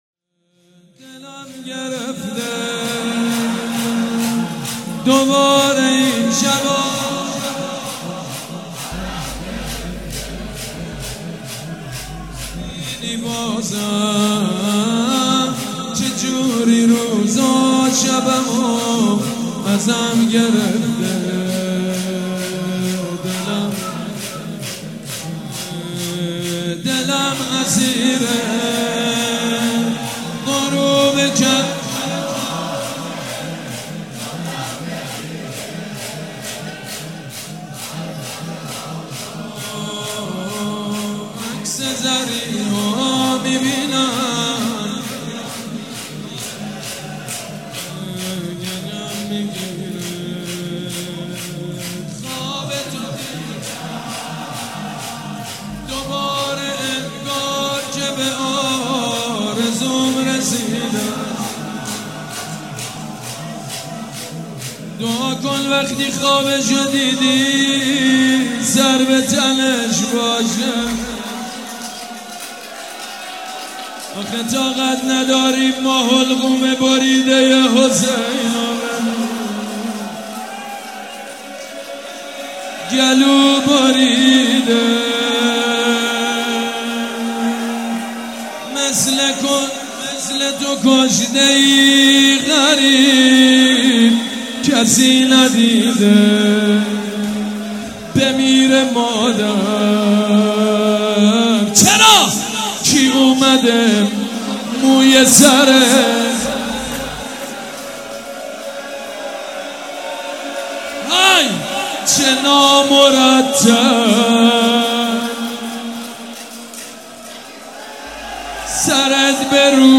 «شهادت امام جواد 1394» شور: دلم گرفته دوباره این شبا برا حرم گرفته